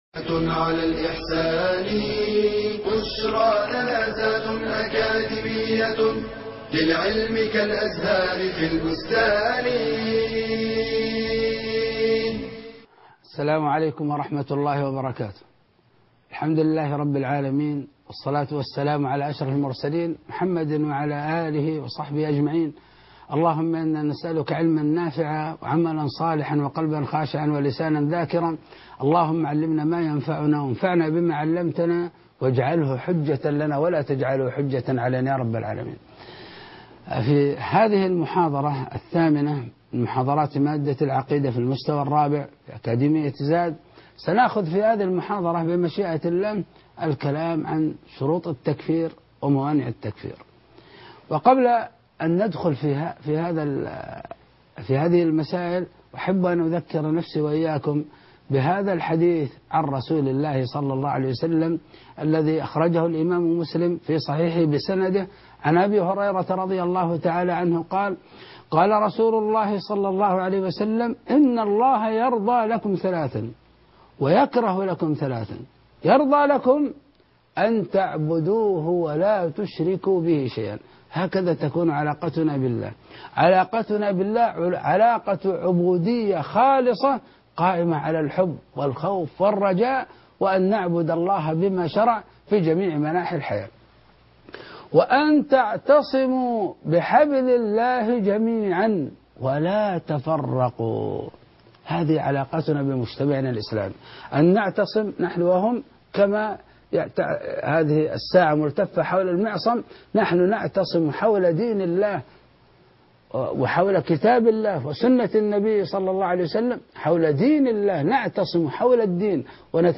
الدرس الثامن_ ثلاثا